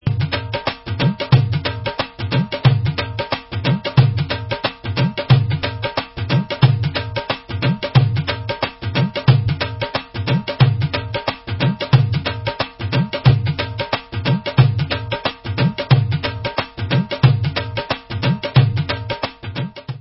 Dholak & Dhol 3